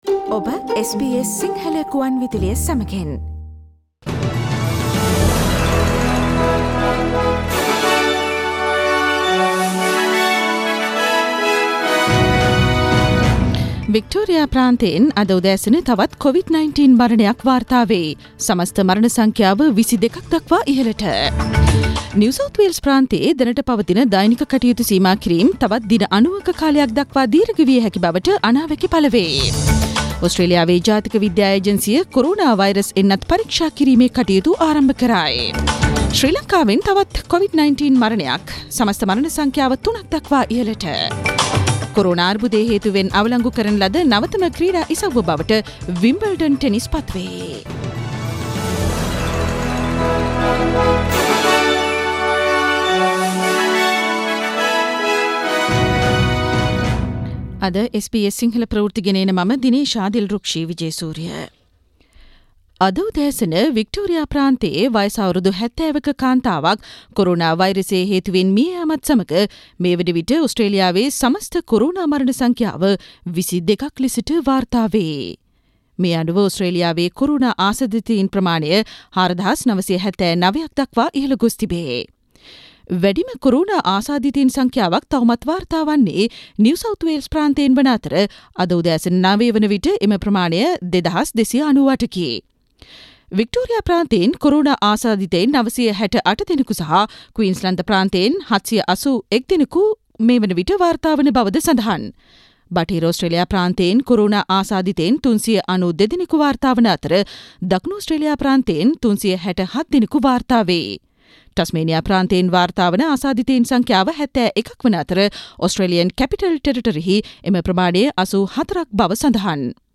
Today’s news bulletin of SBS Sinhala radio – Thursday 02 April 2020